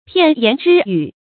片言只语 piàn yán zhī yǔ
片言只语发音
成语正音 只，不能读作“zhǐ”。